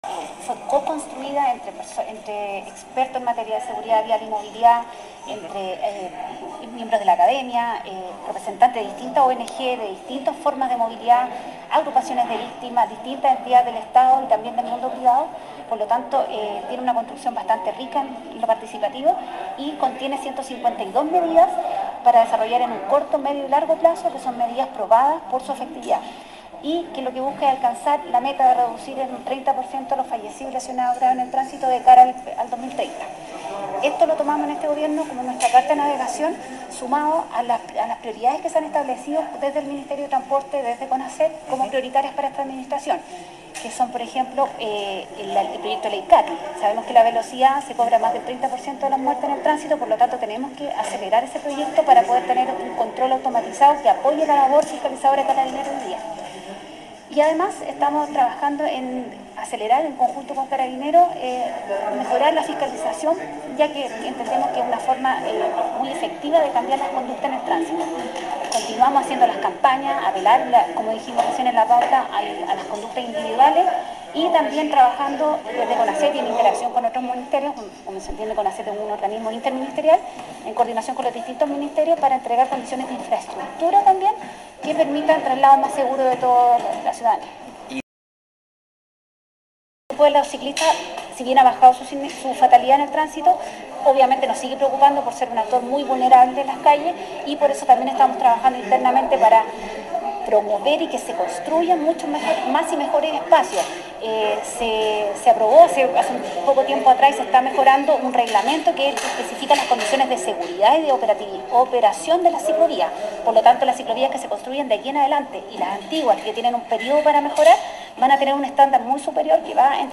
Cuña-Conaset.mp3